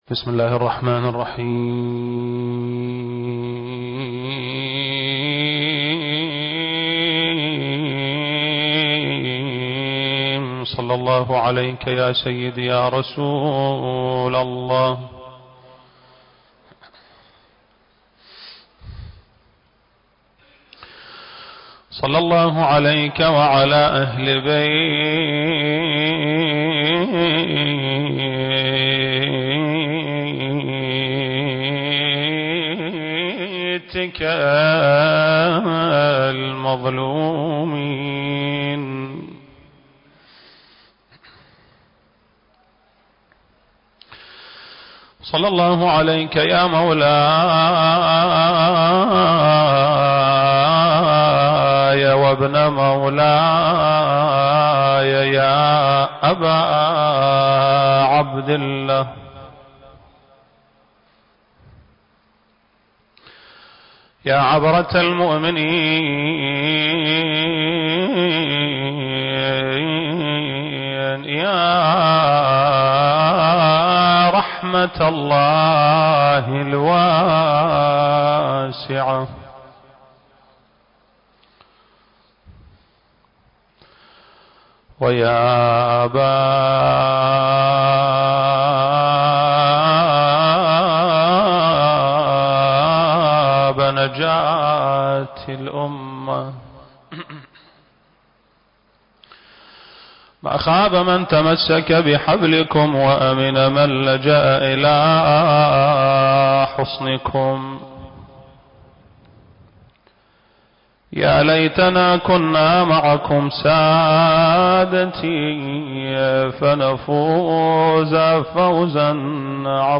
المكان: مسجد آل محمد (صلّى الله عليه وآله وسلم) - البصرة التاريخ: 1442